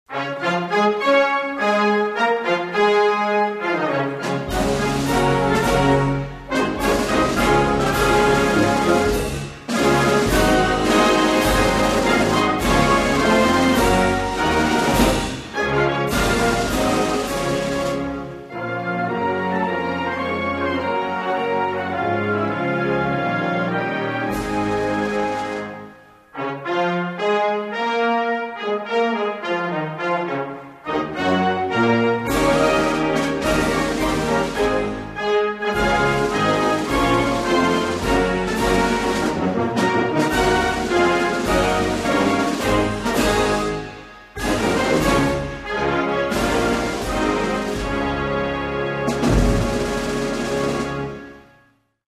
Togo's_national_anthem_(instrumental).mp3